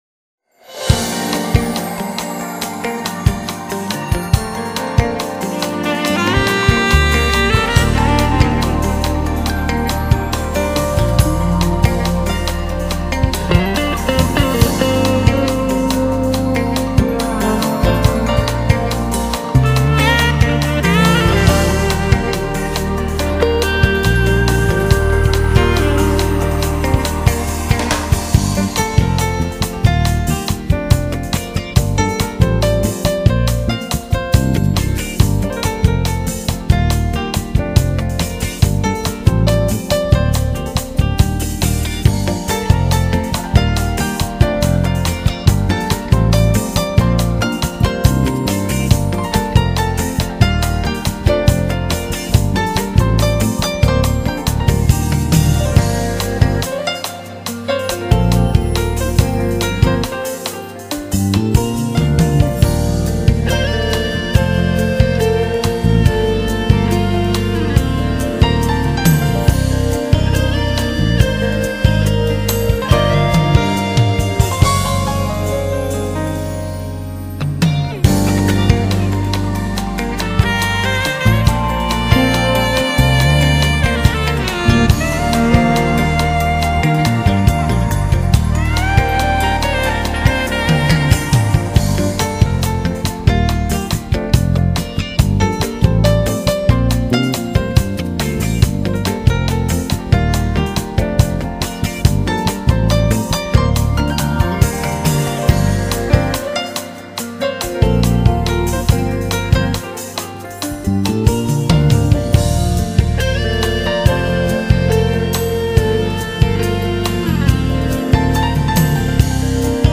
Genre: Jazz, Smooth Jazz
他的音乐，演奏旋律性强，R&B与Funk味并重，非常时尚，深受都市年轻人的喜爱。